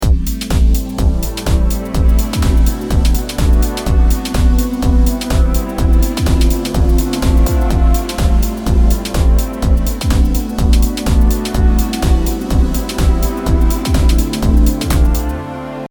LFOではボリュームのみをゆっくり変化させることで、リバースの雰囲気も得られつつ、音に厚みが増していてこれなら使いどころがありそうな気がします。これに、リバーブを深めに足してドラムやベースを加えてみるとどうなるでしょう？
妖艶な雰囲気を演出できたような気がするのですが皆さんはいかがでしょう？